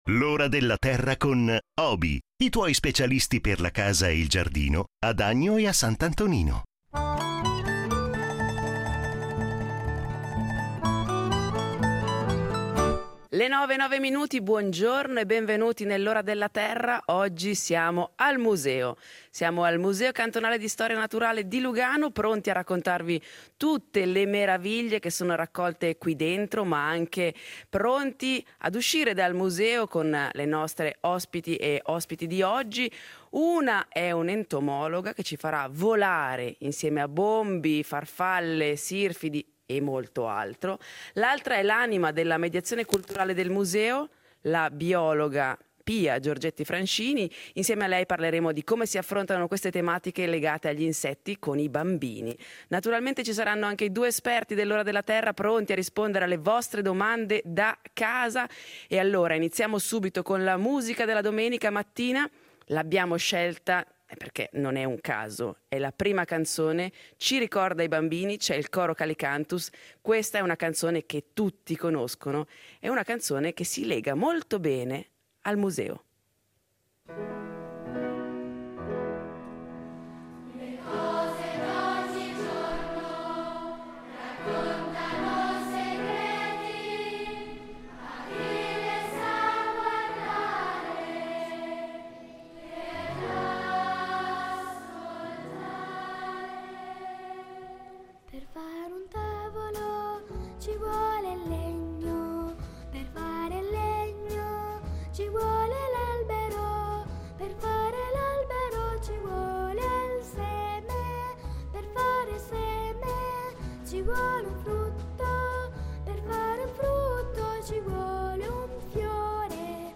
Ne parliamo in diretta dal Museo cantonale di storia naturale di Lugano